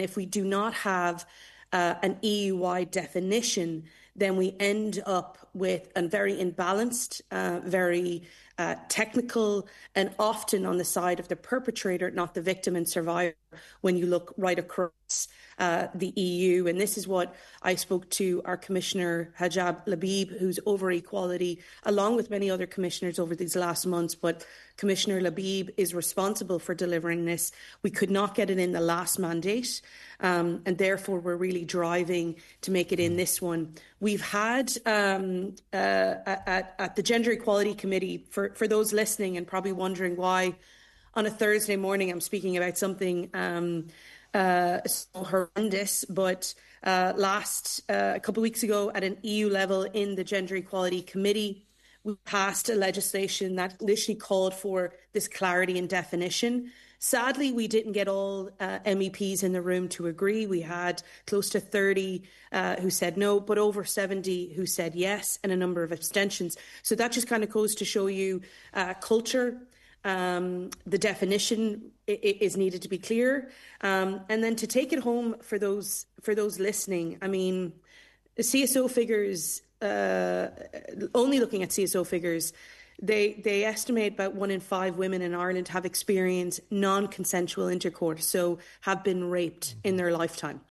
Ms Walsh explained some of the work ongoing to achieve her call